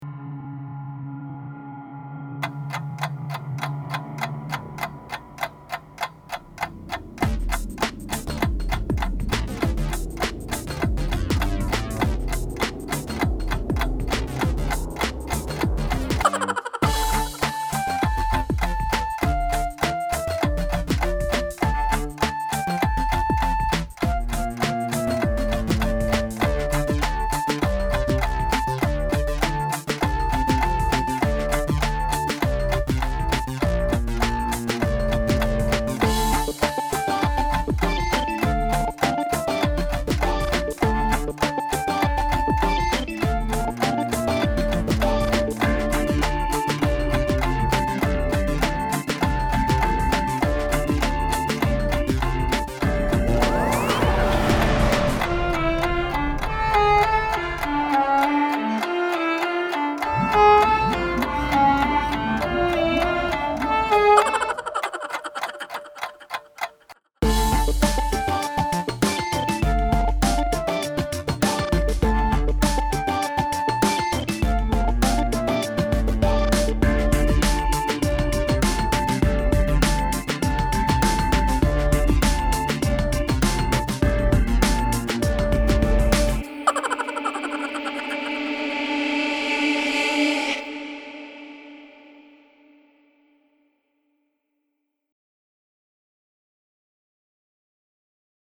Los_esqueletos_con_flauta.mp3